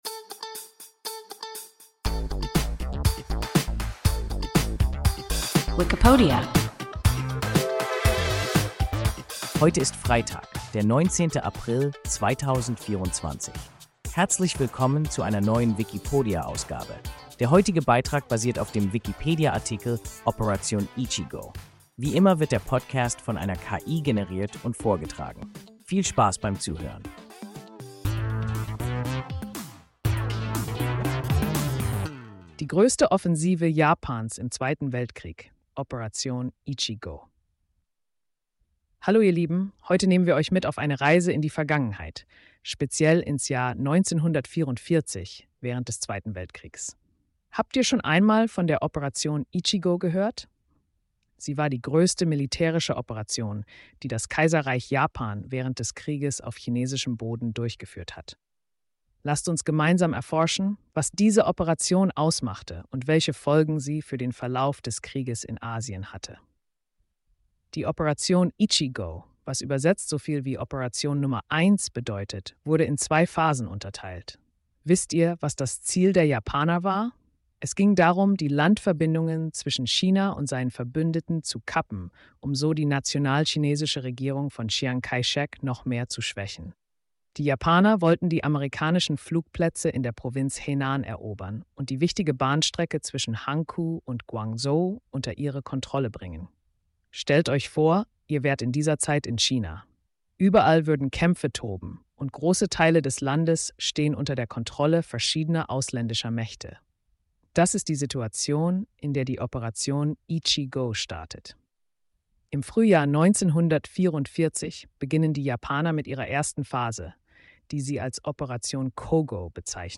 Operation Ichi-gō – WIKIPODIA – ein KI Podcast